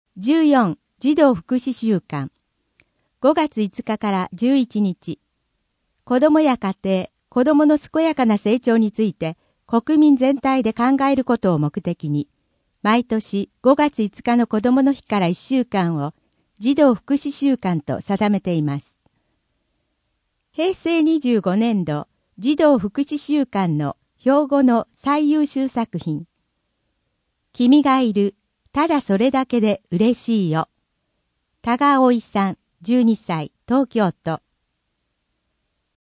声の「広報はりま」5月号
声の「広報はりま」はボランティアグループ「のぎく」のご協力により作成されています。